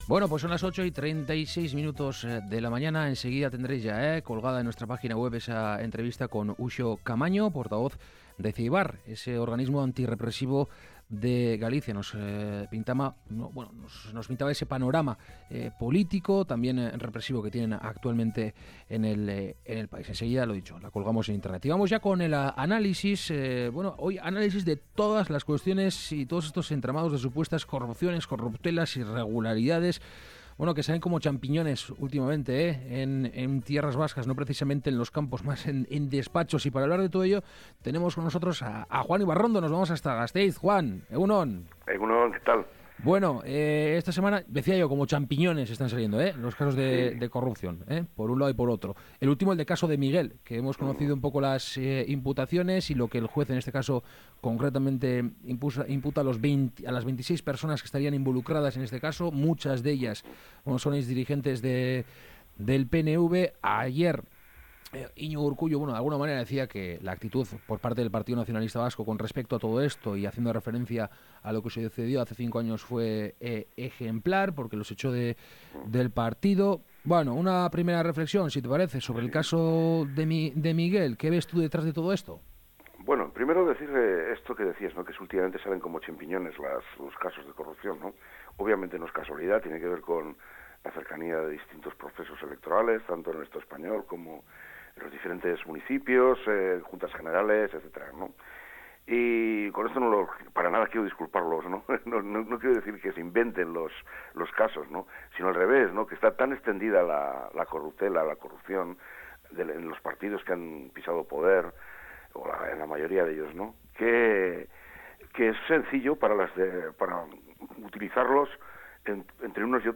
La tertulia de Kalegorrian: corrupción